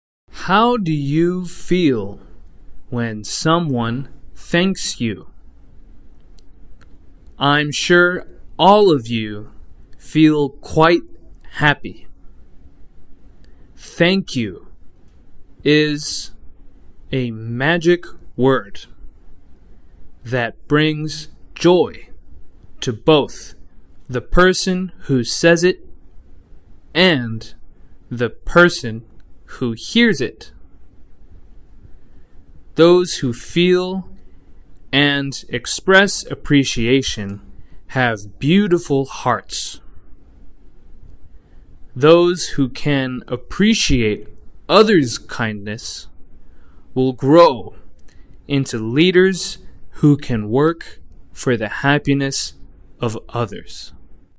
模範音声再生（スロー版） 模範音声再生（スロー版）
s_slow_english2025_model.mp3